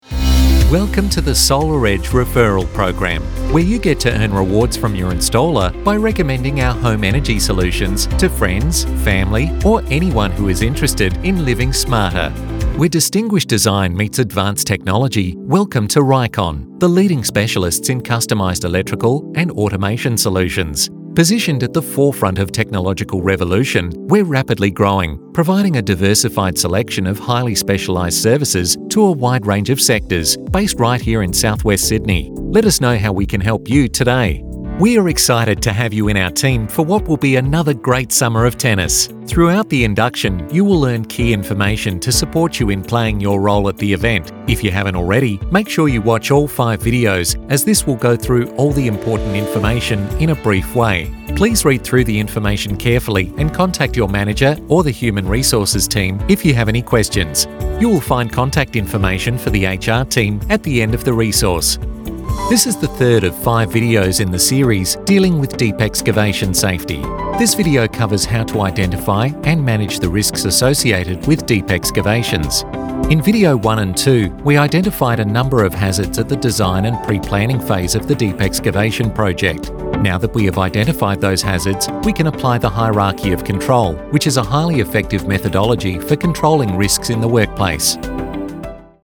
Never any Artificial Voices used, unlike other sites.
Male
English (Australian)
Adult (30-50)
Corporate
0710Corporate_Demo.mp3